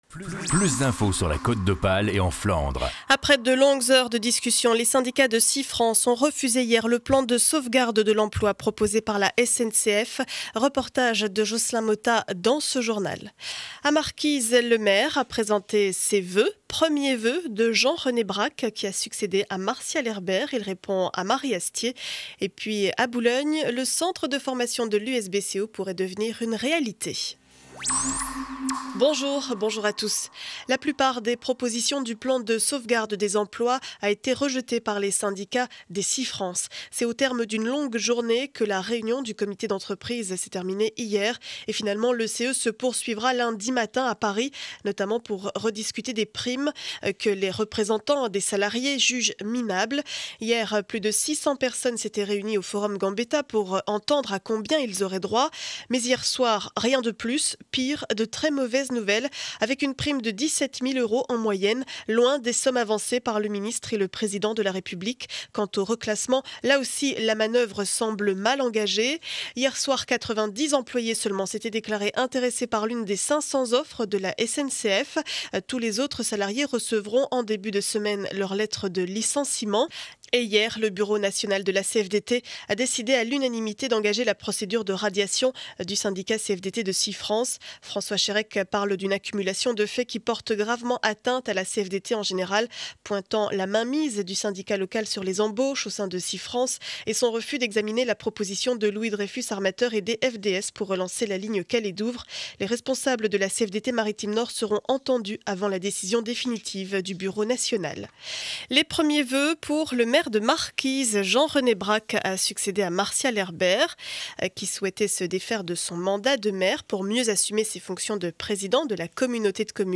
Journal du vendredi 20 janvier 2012 7 heures 30 édition du Boulonnais.